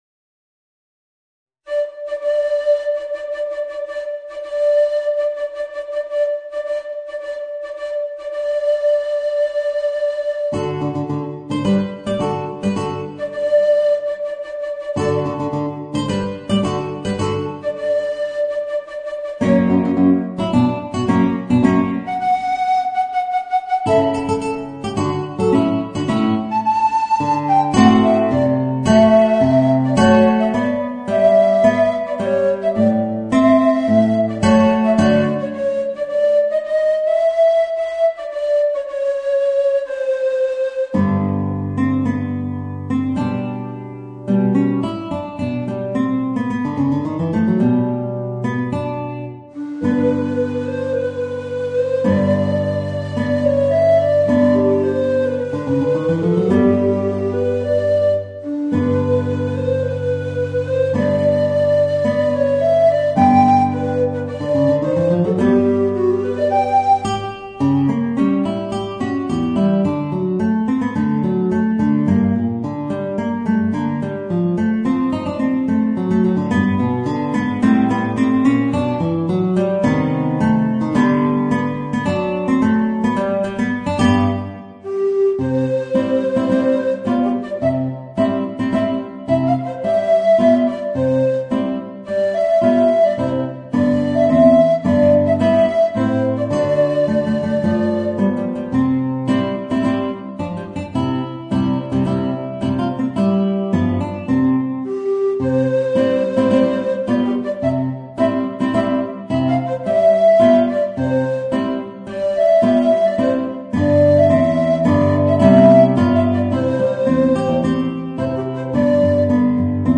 Voicing: Guitar and Tenor Recorder